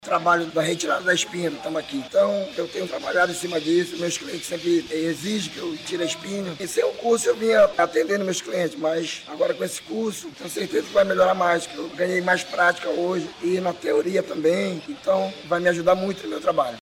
SONORA-2-EXPOAGRO-ITACOATIARA-.mp3